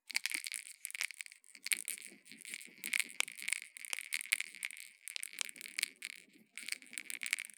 • small toy ball labhyrinth shaker sounds kitchen.wav
Experience the playful, rhythmic sound of a small toy ball labyrinth shaker in a kitchen setting.
small_toy_ball_labhyrinth_shaker_sounds_kitchen_xyO.wav